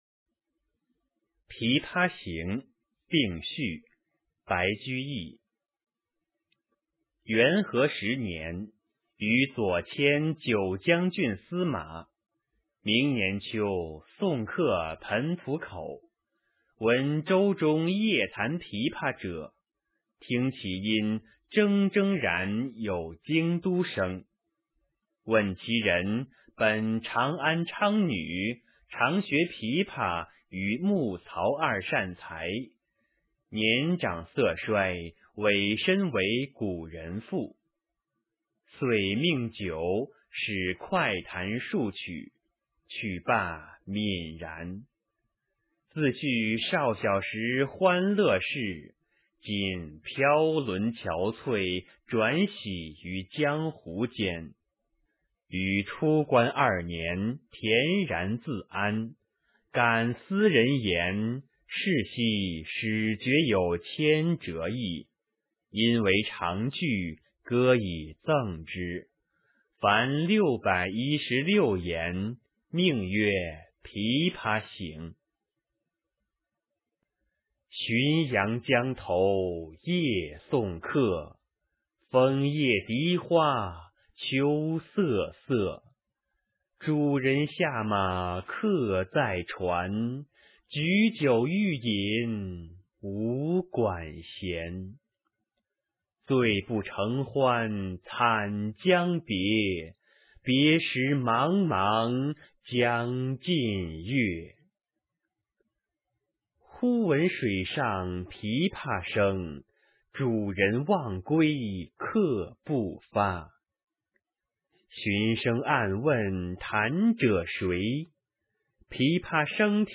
《琵琶行》（并序）原文与译文（含赏析、朗读）　/ 白居易